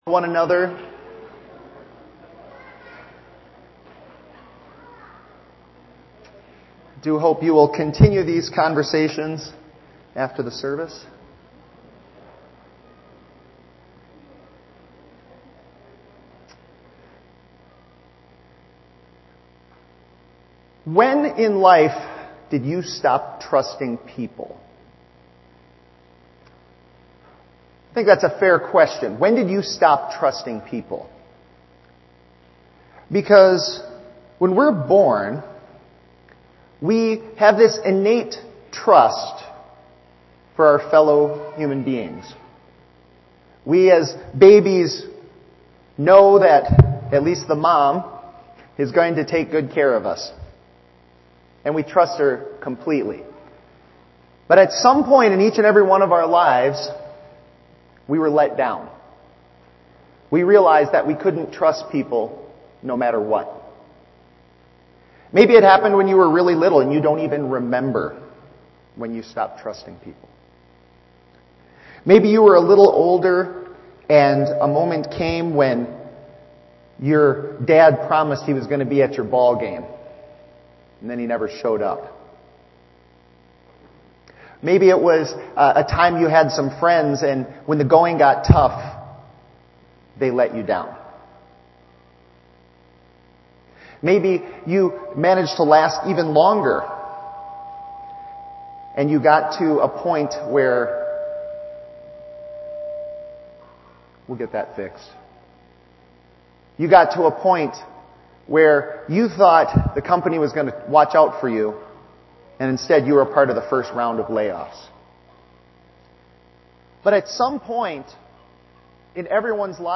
Bible Text: John 10:11-21 | Preacher